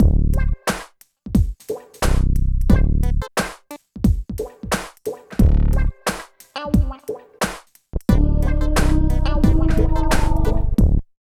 95 LOOP   -R.wav